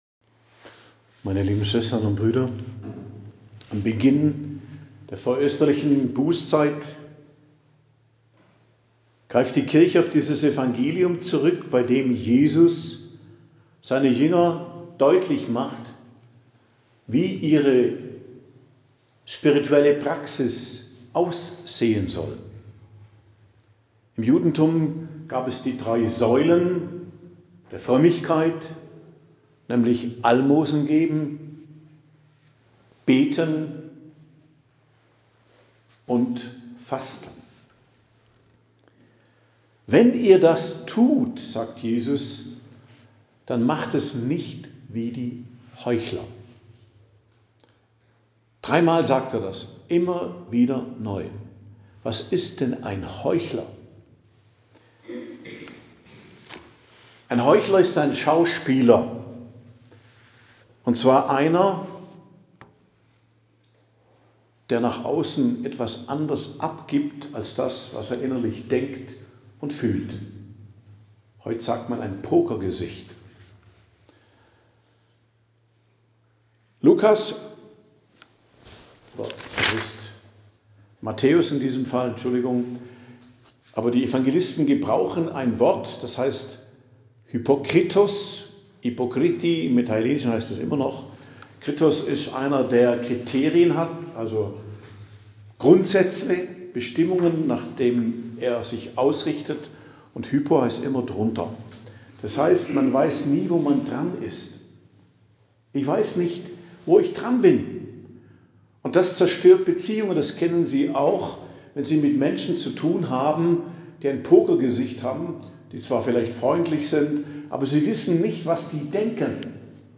Predigt am Aschermittwoch, 5.03.2025